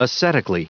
Prononciation du mot ascetically en anglais (fichier audio)
Prononciation du mot : ascetically